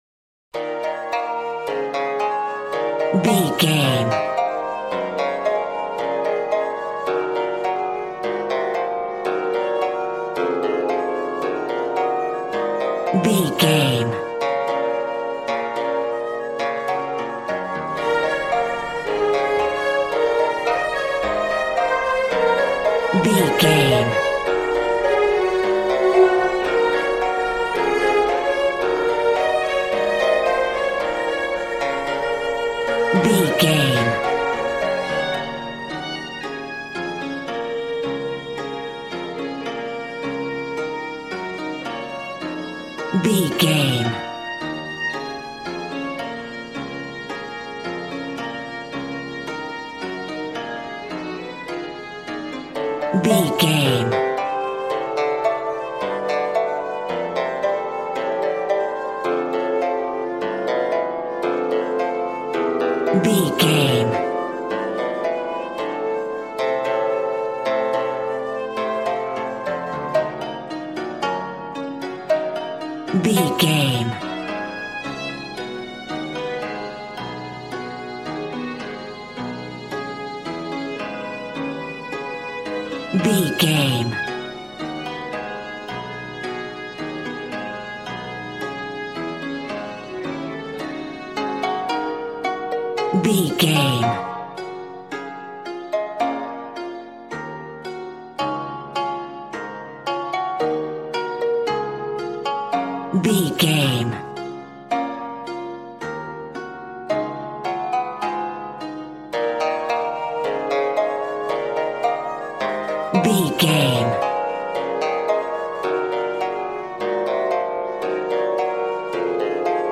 Aeolian/Minor
B♭
smooth
conga
drums